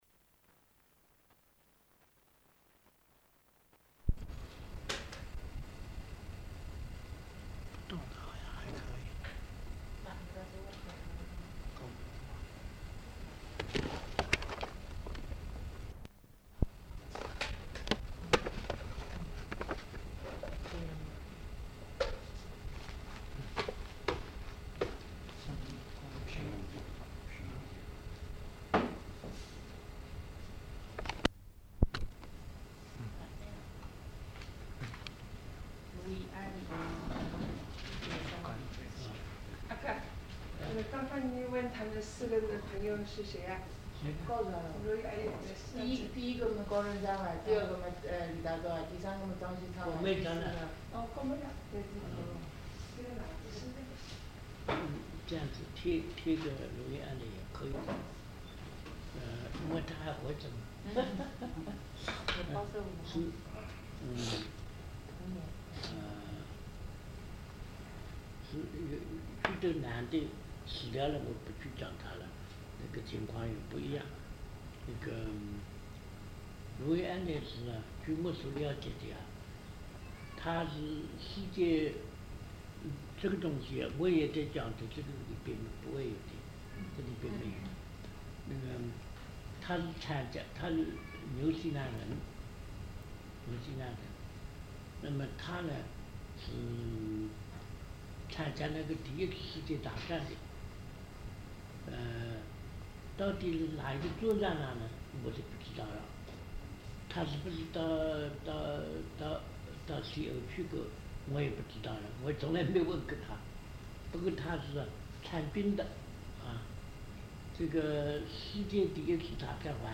采访录音 | 陈翰笙档案资料库